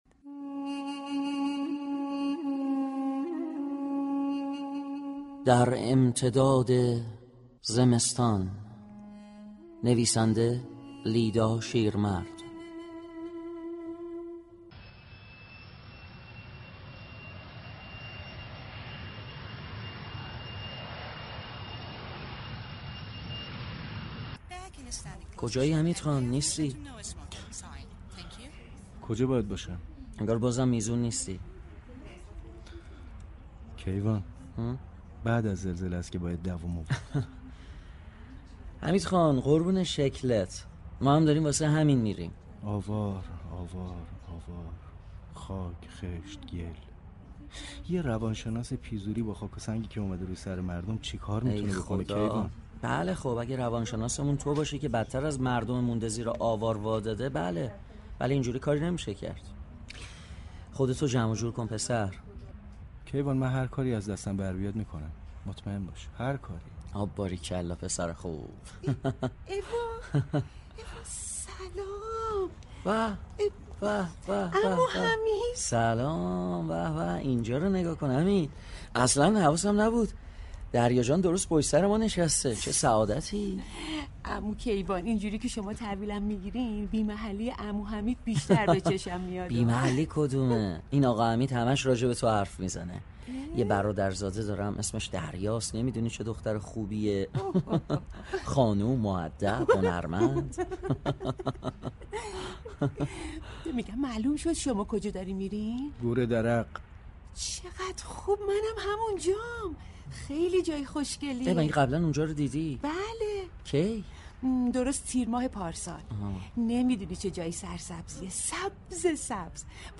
به مناسبت هفته كاهش بلایای طبیعی، نمایش رادیویی